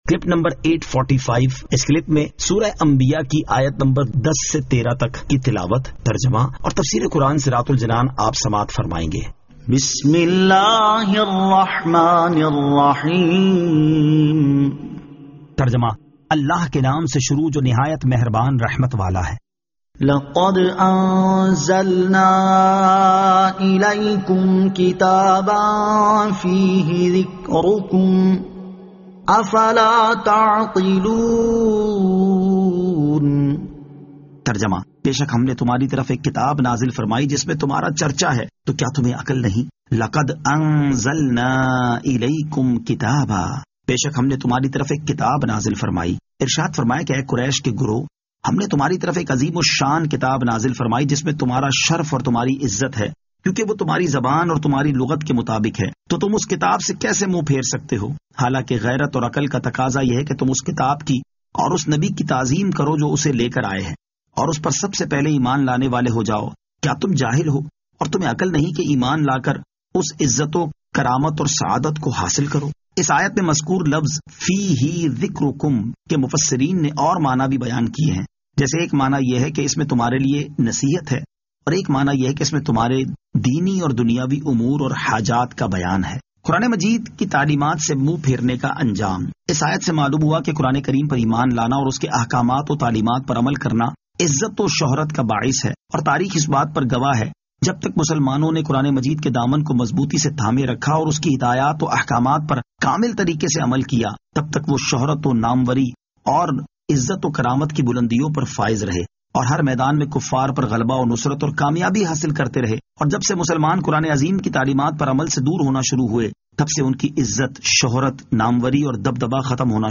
Surah Al-Anbiya 10 To 13 Tilawat , Tarjama , Tafseer